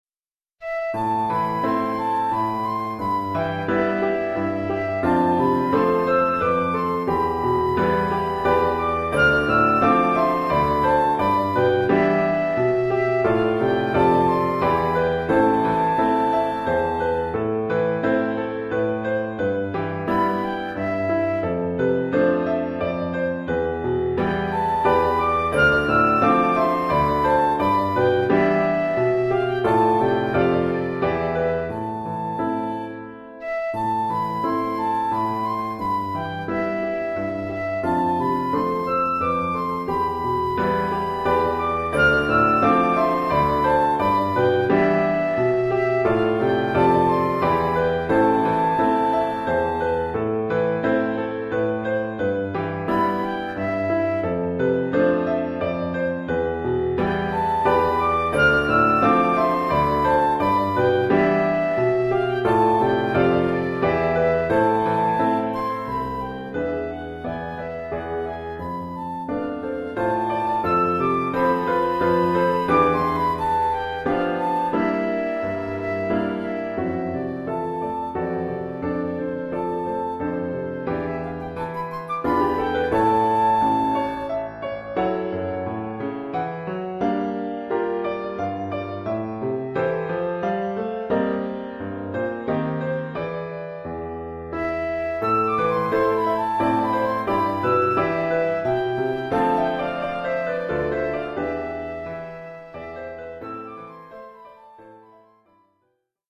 1 titre, flûte et piano : conducteur et partie de flûte
Oeuvre pour flûte et piano.